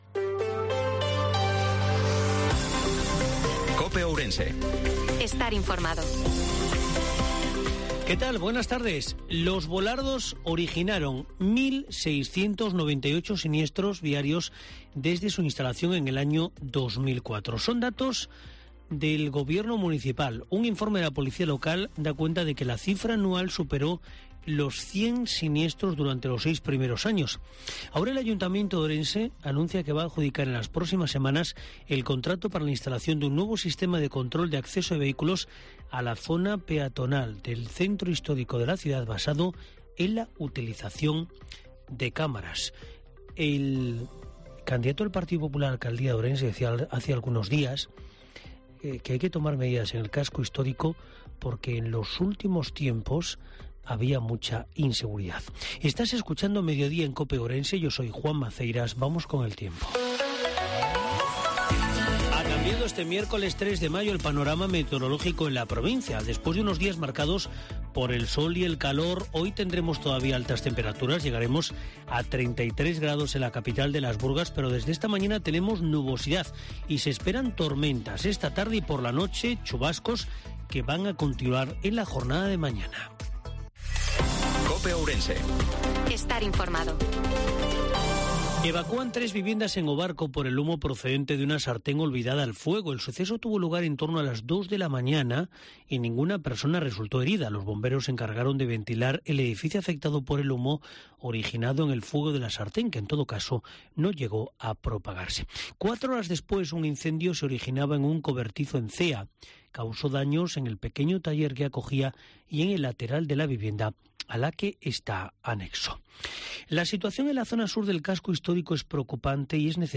INFORMATIVO MEDIODIA COPE OURENSE-03/05/2023